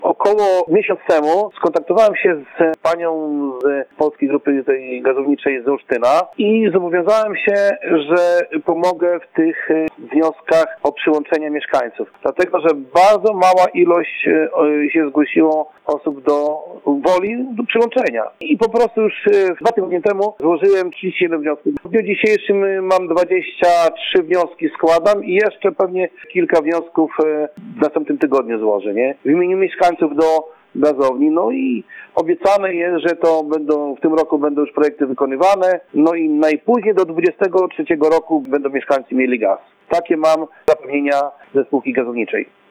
Jak mówi Bogusław Wisowaty, radny miejski, o takiej możliwości wiadomo od dawna.